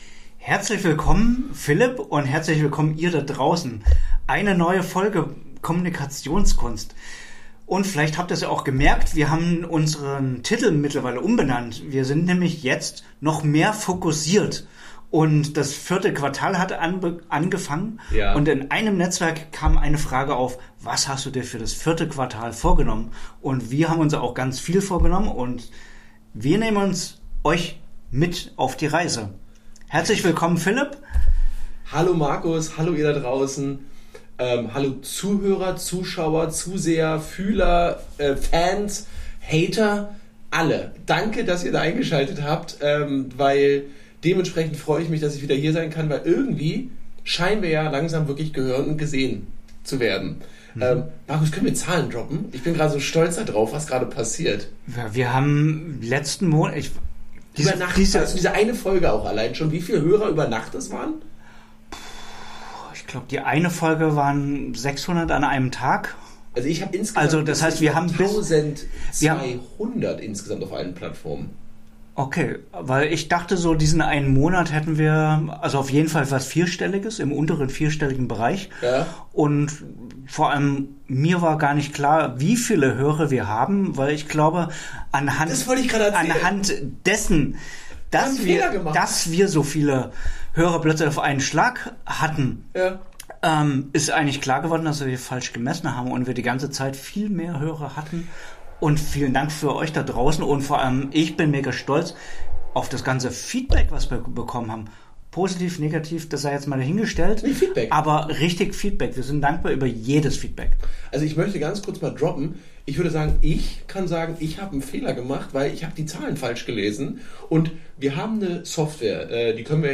Die Zahlen verändern sich, das Team wächst – und mit dem Erfolg steigen auch Verantwortung, Komplexität und Kosten. Doch was bedeutet das konkret für Marketing, Vertrieb und die tägliche Arbeit als Unternehmer? In dieser Folge sprechen die beiden offen über: